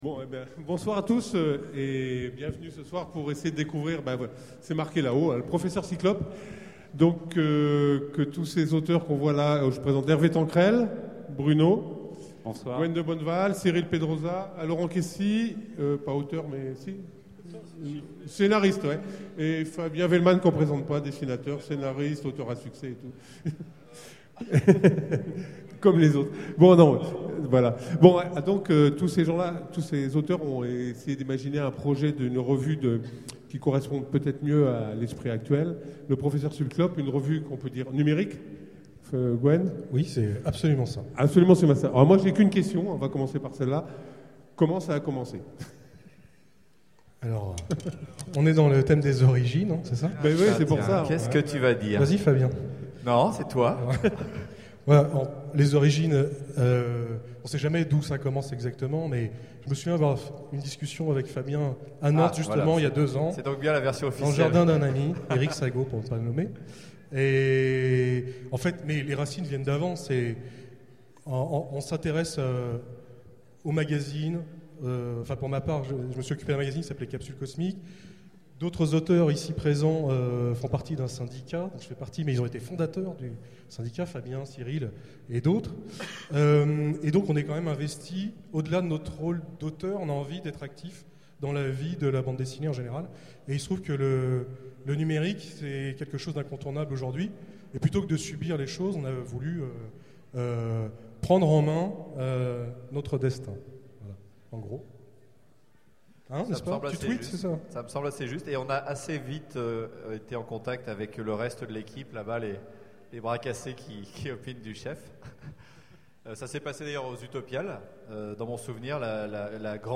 Utopiales 12 : Conférence Professeur Cyclope en vue !